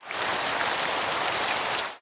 Minecraft Version Minecraft Version 25w18a Latest Release | Latest Snapshot 25w18a / assets / minecraft / sounds / ambient / weather / rain1.ogg Compare With Compare With Latest Release | Latest Snapshot
rain1.ogg